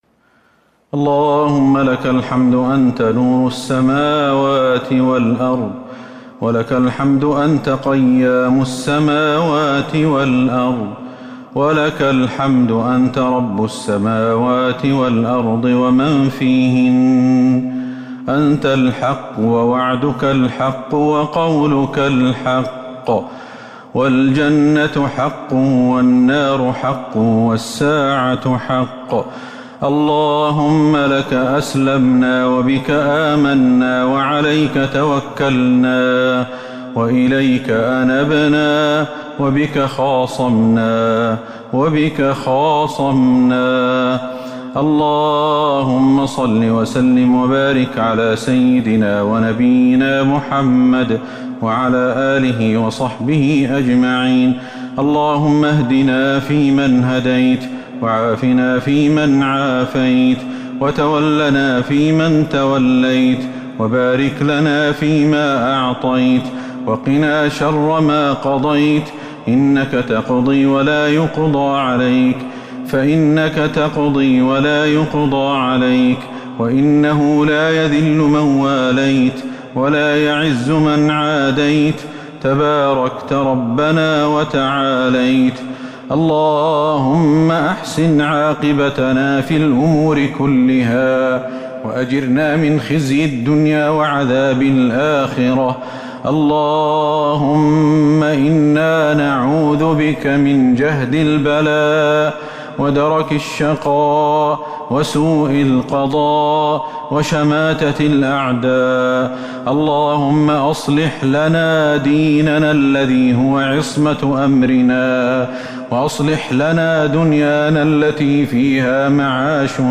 دعاء القنوت ليلة 11 رمضان 1442هـ | Dua for the night of 11 Ramadan 1442H > تراويح الحرم النبوي عام 1442 🕌 > التراويح - تلاوات الحرمين